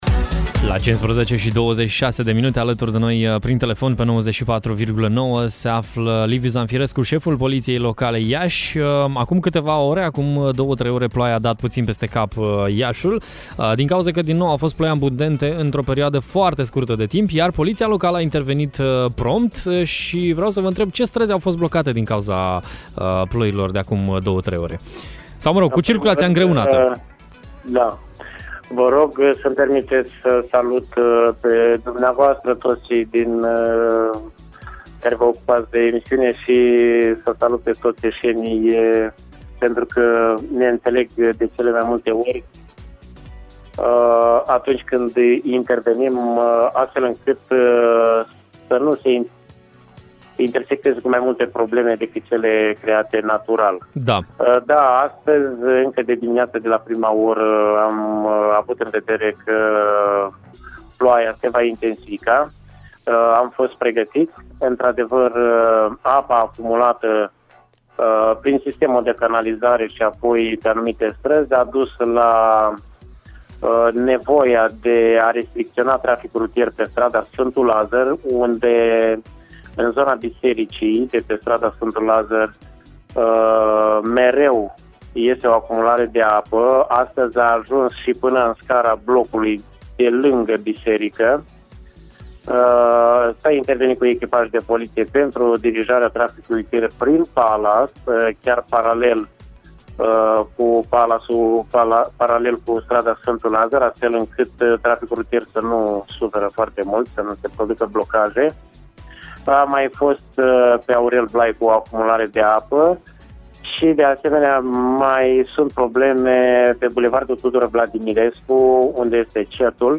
La aceste întrebări ne-a răspuns în direct la Radio Hit, Liviu Zamfirescu – șeful Poliției Locale Iași: